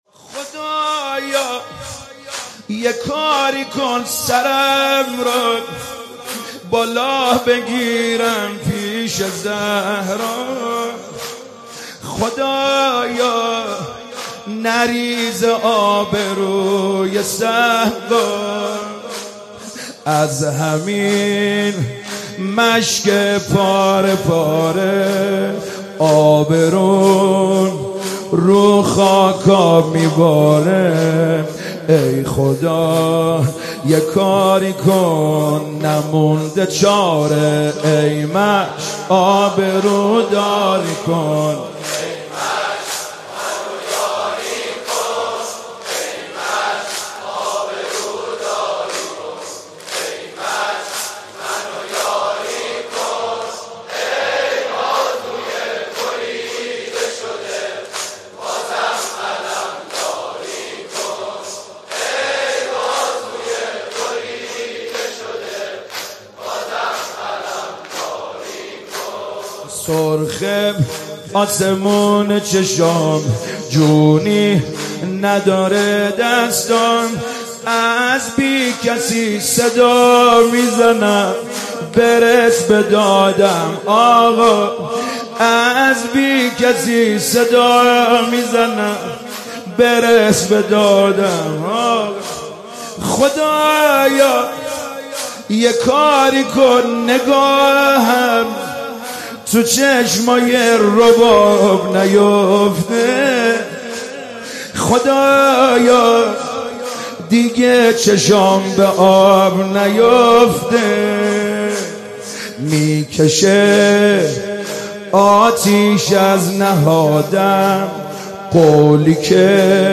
مداحی جدید
شب نهم محرم97 شب تاسوعا هیات کربلا رفسنجان